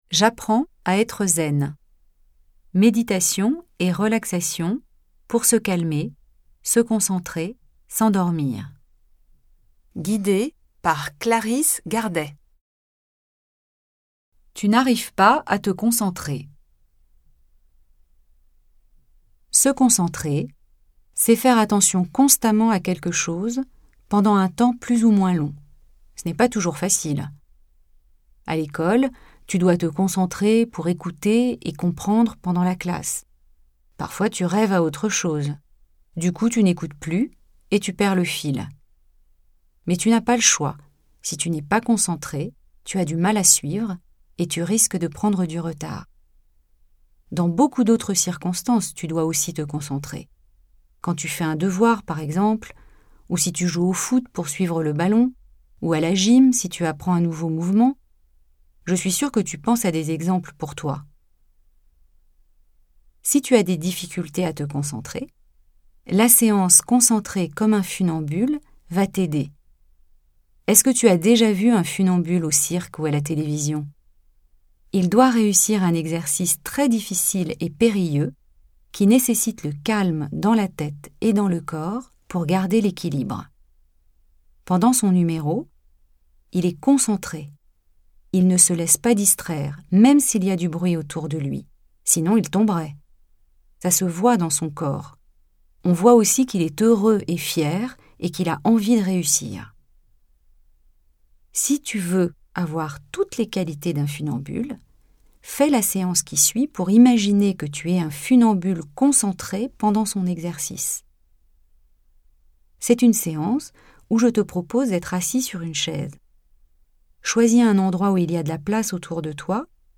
Chaque séance est précédée d’une explication simple pour préparer la relaxation guidée qui suit.